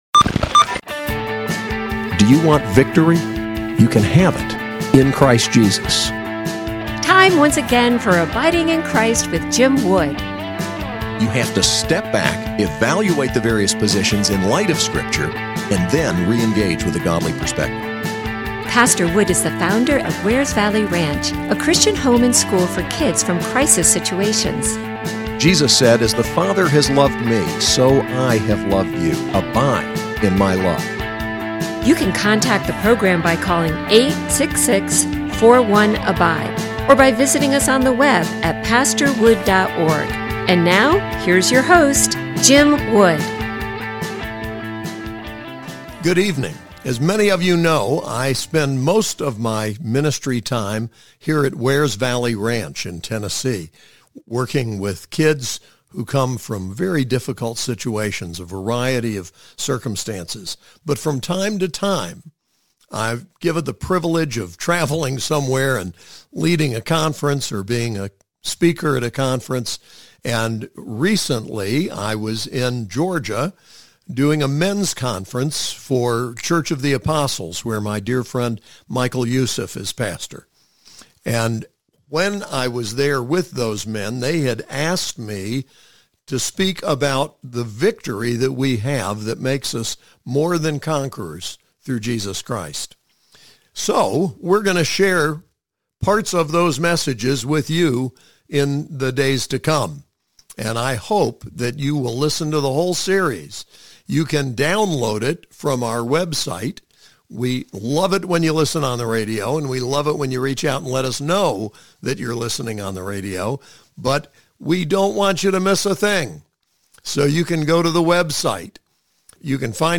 Series: COA Men's Retreat